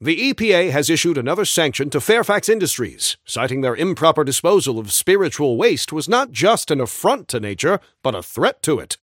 [[Category:Newscaster voicelines]]
Newscaster_headline_77.mp3